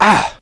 vs_fScarabx_hit1.wav